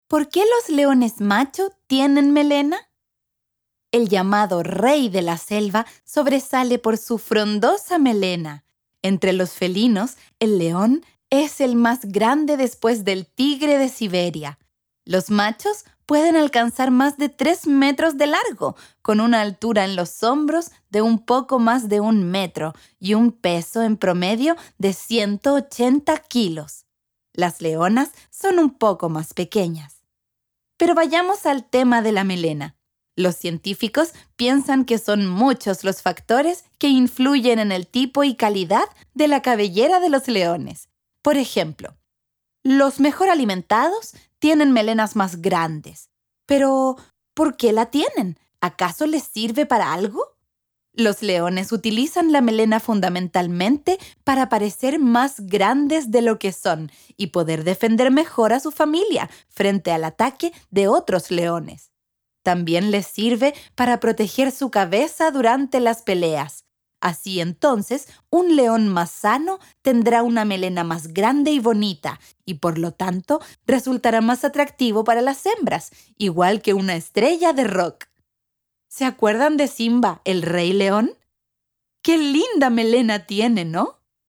Audiocuentos